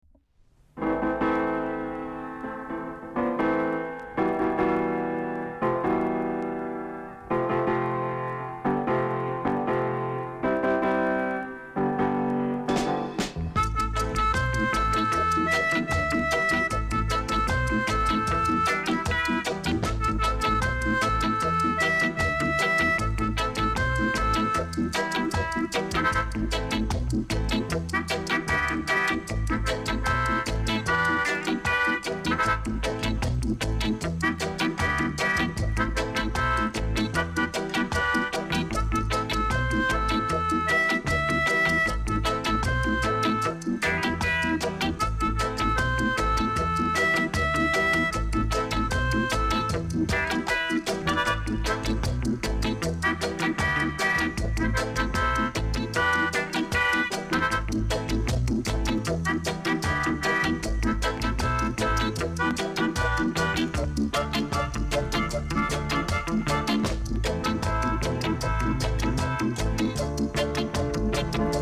KILLER INST!!